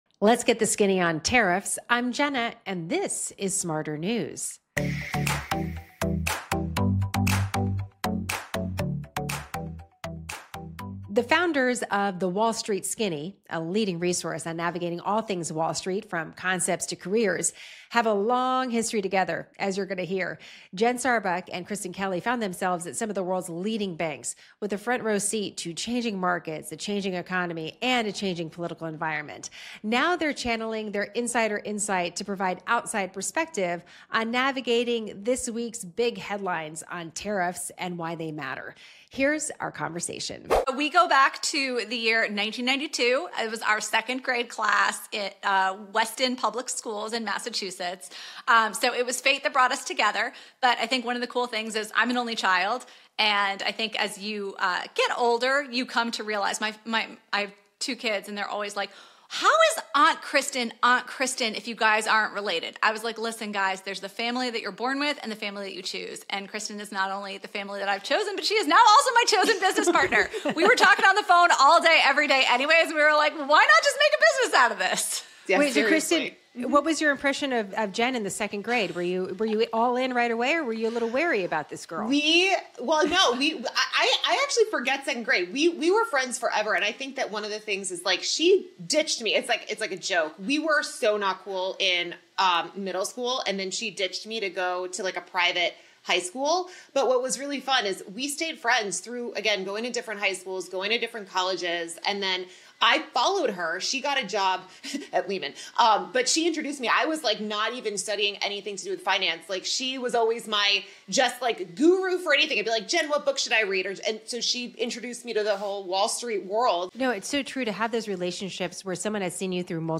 Here's our conversation. 0:40.5 We go back to the year 1992.